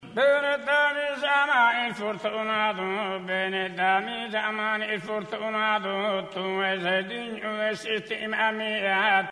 This "coro" form of chanting requires a group of four male voices, referred to as "Oche", "Bassu ","Contra", and "Mesu Oche".
The timber is tenor or baritone for the "Oche"; guttural and in the throat (ingolato) for the "Bassu" (base), il "Contra", is a hollow and blurry voice (sfocato); and the "Mesu Oche", is the falsetto.
Examples of Tenores' Chants